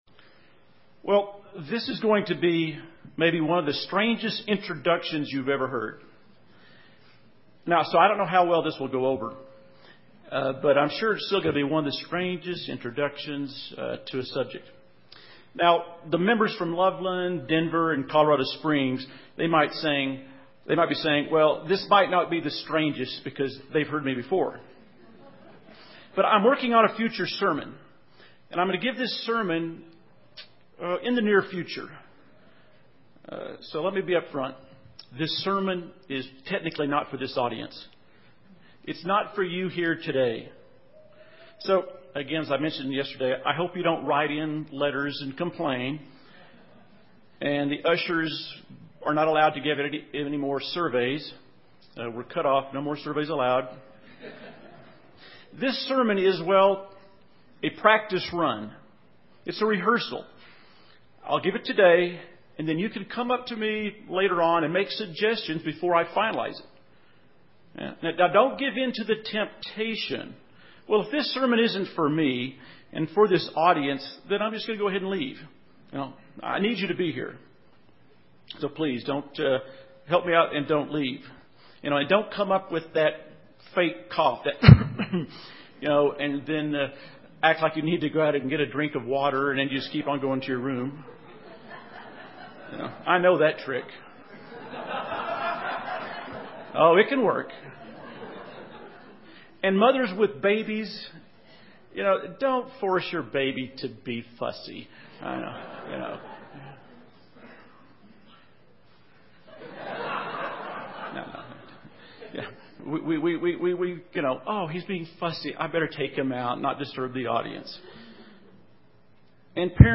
This sermon was given at the Steamboat Springs, Colorado 2011 Feast site.